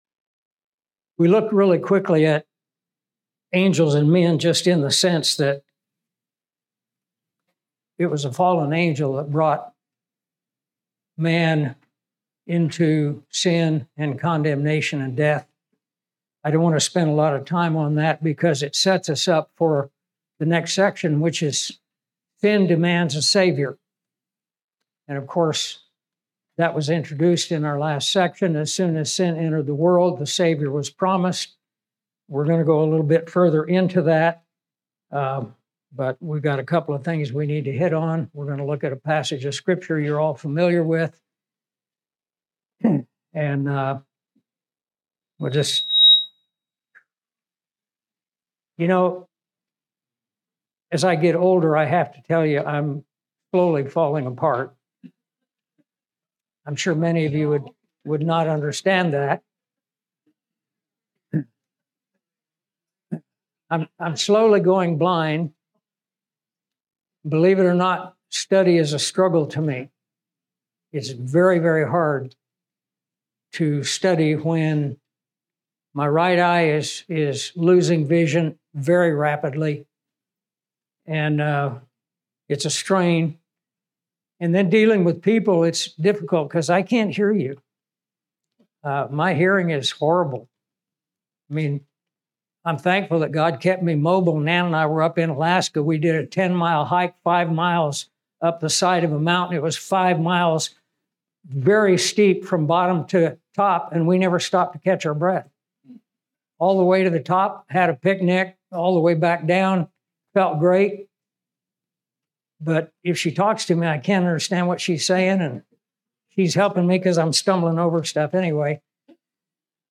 This Bible conference featured seven lesson extracted from The Basics Book.